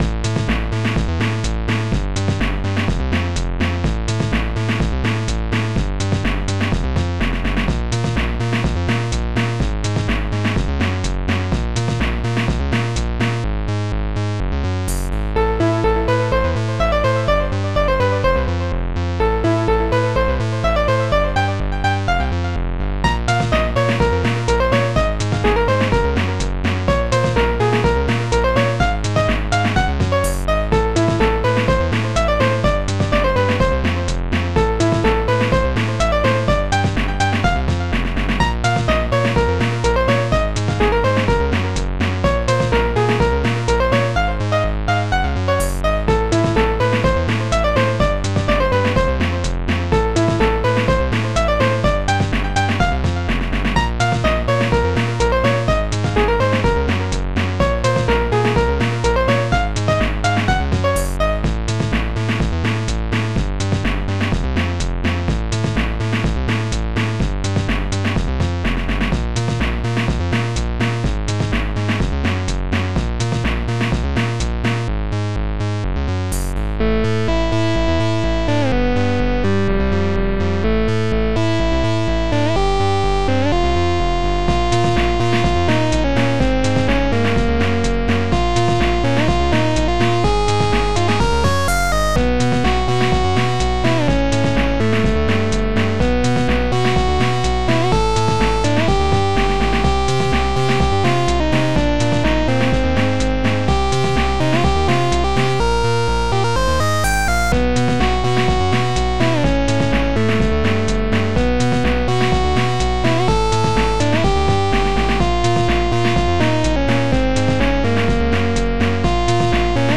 OctaMED Module
Type MED/OctaMED (4ch) Tracker MED 2.10 MMD0